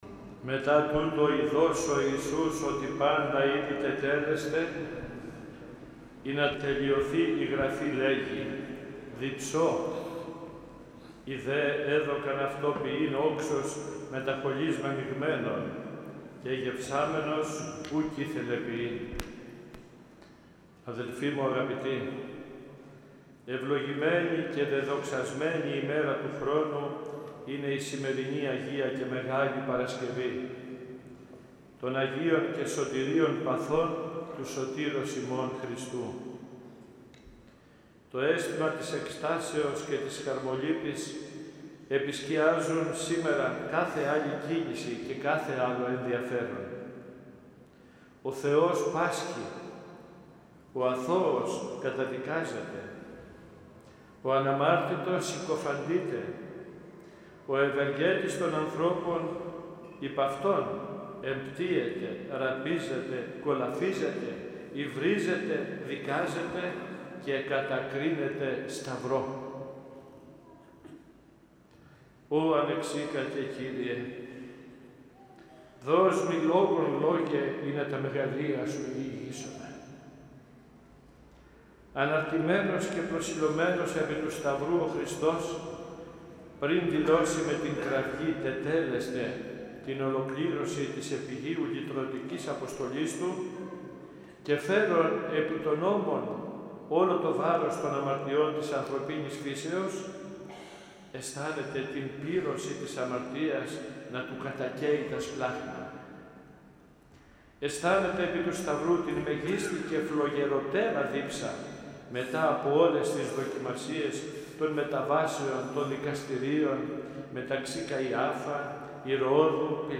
Με ιδιαίτερη συγκίνηση και κατάνυξη τελέσθηκαν το πρωί της Μεγάλης Παρασκευής , 26 Απριλίου οι Ακολουθίες των Μεγάλων Ωρών και του Εσπερινού της Αποκαθηλώσεως στον Ιερό Μητροπολιτικό Ναό Ευαγγελισμού της Θεοτόκου Λαμίας χοροστατούντος του Σεβασμιωτάτου Μητροπολίτου μας κ. Νικολάου.
dipso_omilia_sevasmiotatoy_esperinos_apokathikoseos_2019.mp3